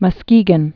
(mŭ-skēgən)